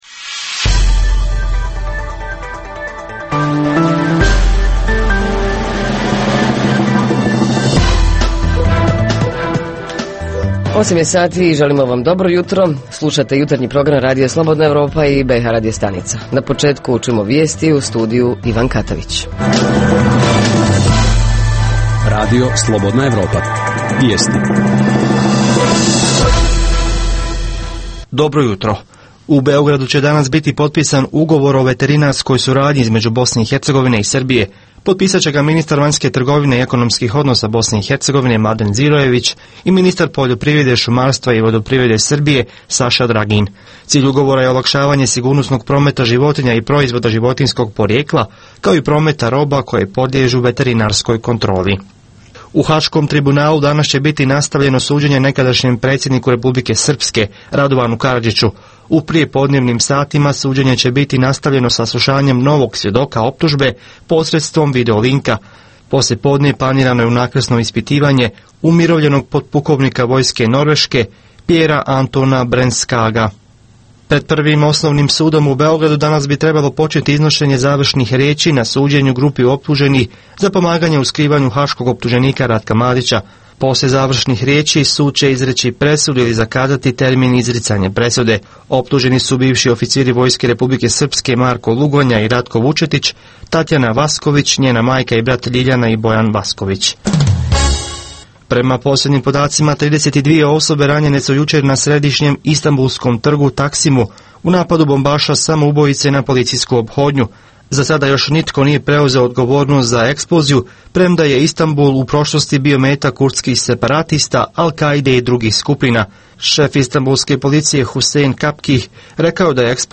Jutarnji program za BiH koji se emituje uživo. Ponedjeljkom govorimo o najaktuelnijim i najzanimljivijim događajima proteklog vikenda.
Redovni sadržaji jutarnjeg programa za BiH su i vijesti i muzika.